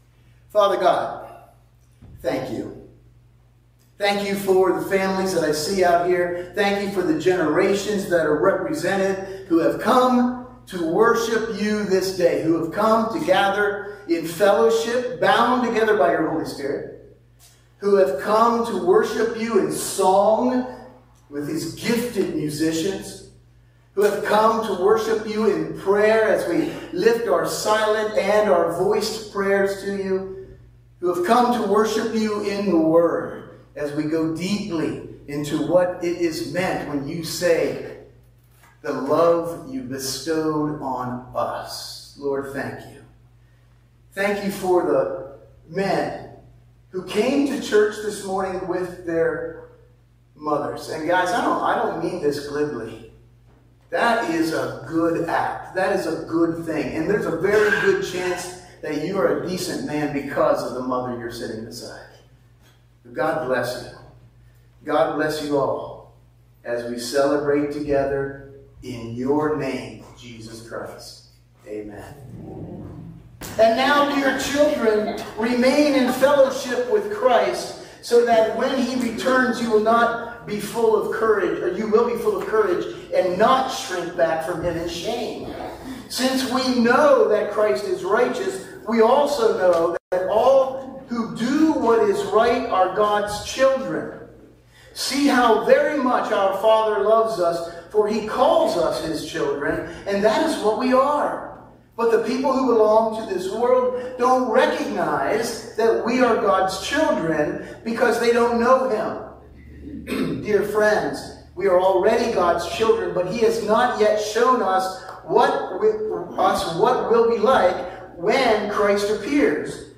A special Mother’s Day service with Brass Triumphant at Churchtown Church of God.